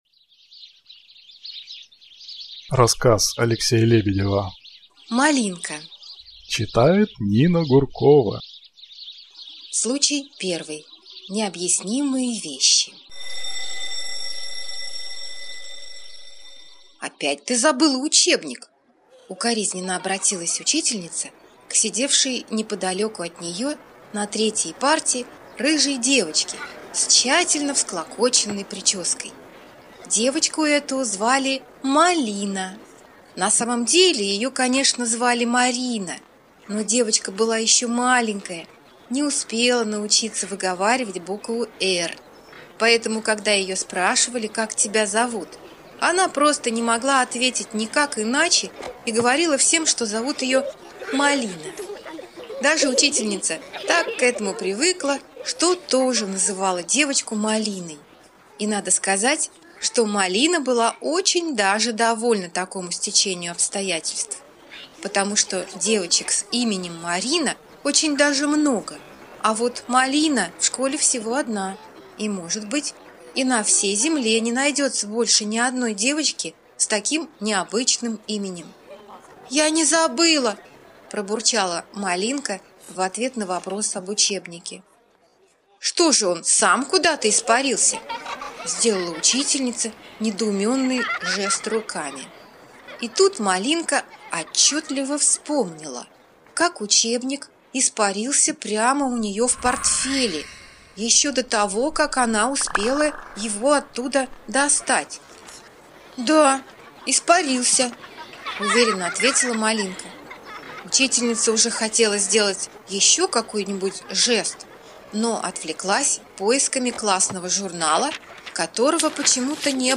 Малинка - аудиосказка Лебедева - слушать онлайн